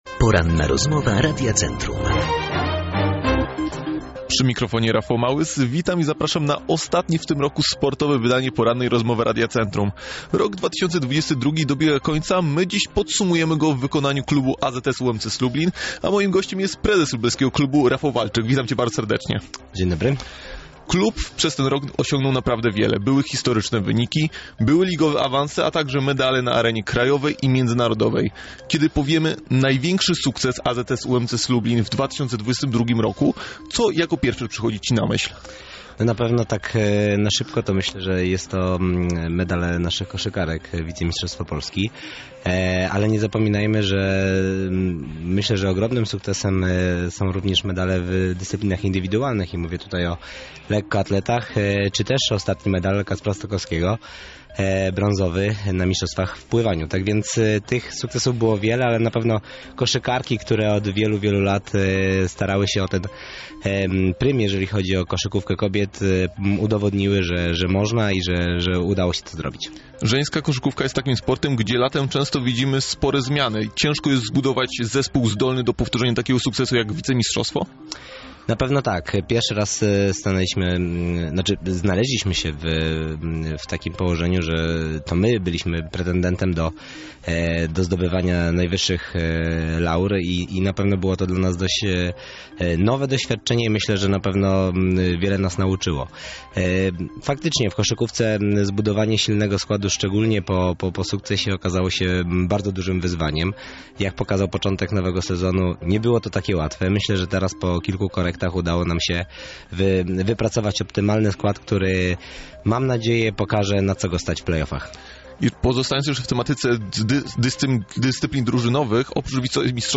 Rozmowa po edycji
Rozmowa-po-edycji.mp3